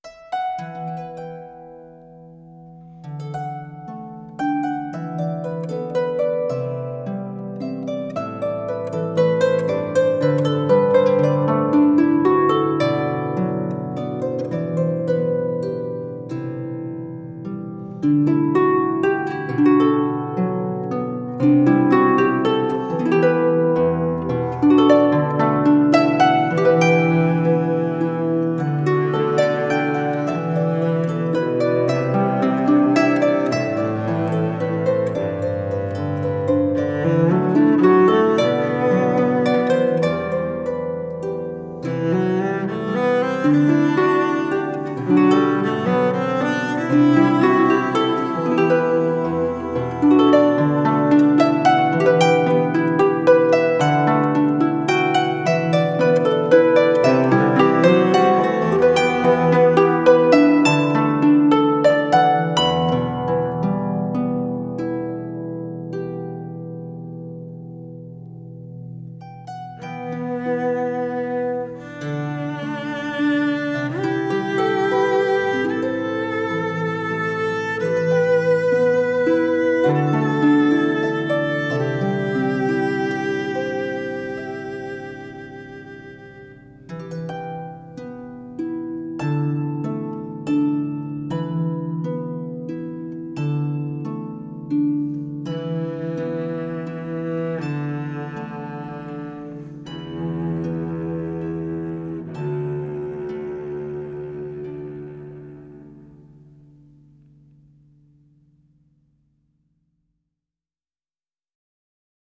This heartfelt piece is suitable for all occasions.
cello